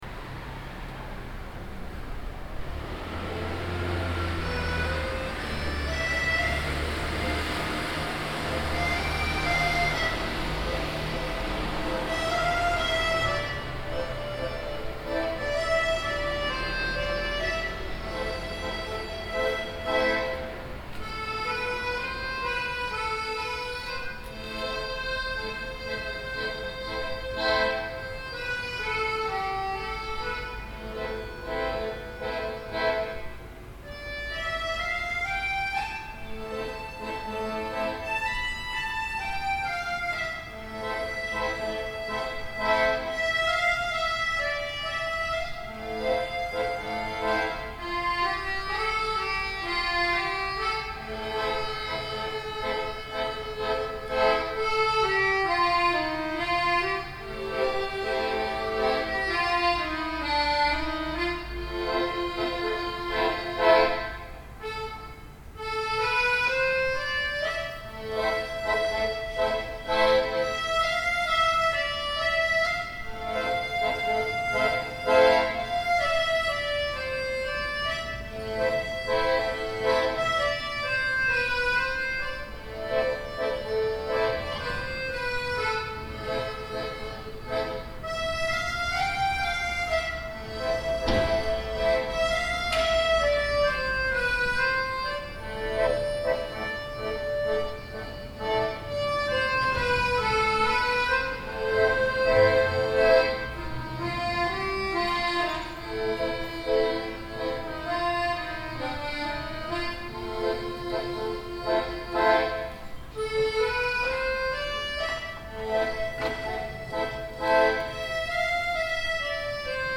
Fisarmonica - Città Metropolitana di Torino...
Rumore
A volte i suoni li vai a cercare, altre volte ti raggiungono sul terrazzo di casa Torino
Microfoni binaurali stereo SOUNDMAN OKM II-K / Registratore ZOOM H4n
Fisarmonica.mp3